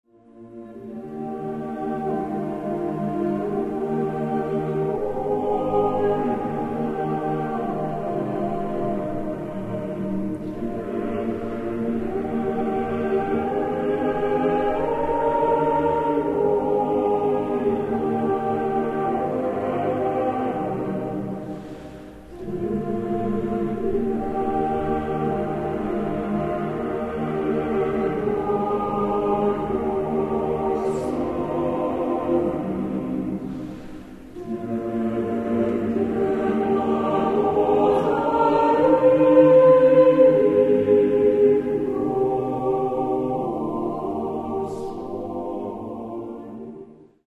Каталог -> Другое -> Relax-piano, музыкальная терапия